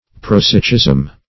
Prosaicism \Pro*sa"i*cism\, n.